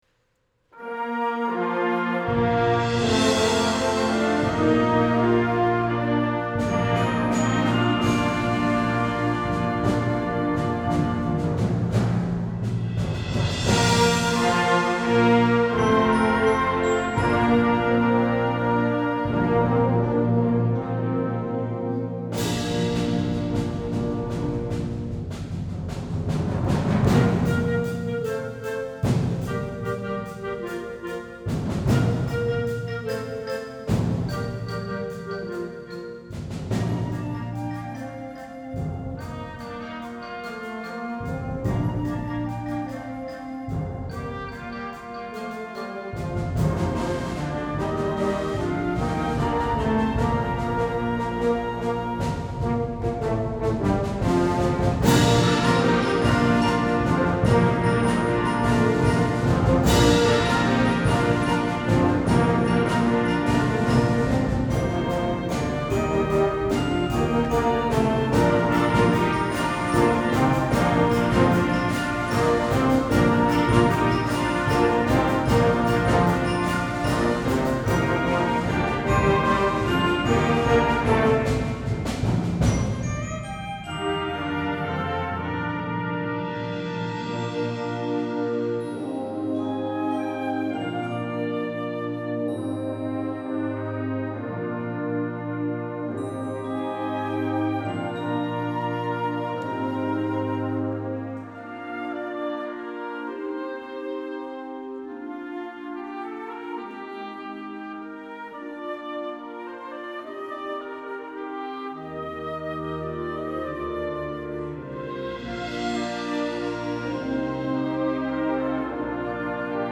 Genre: Band
Instrumentation
Flute
Bass Clarinet
Tenor Saxophone
F Horn
Trombone
Tuba
Timpani (3 drums)
Percussion 1 & 2: Snare Drum, Bass Drum